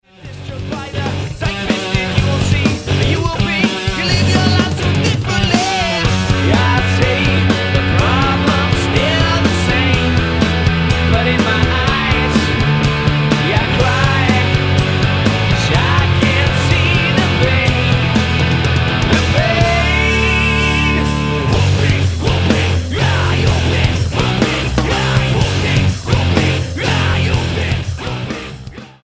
Hard Music Single
Style: Rock